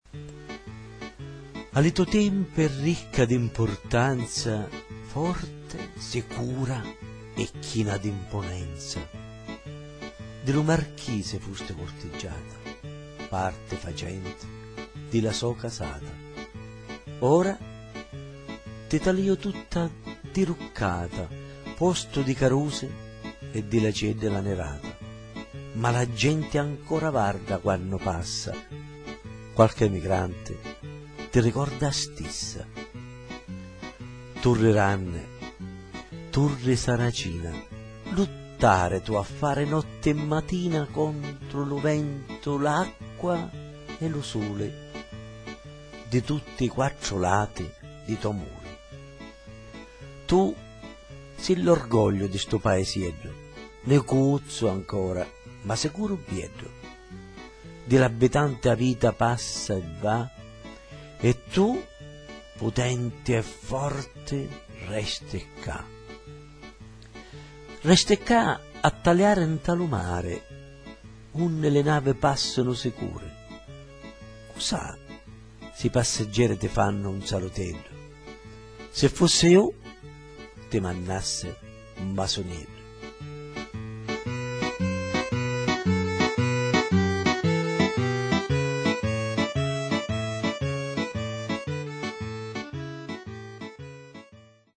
UN CANTO DEDICATO ALLA TORRE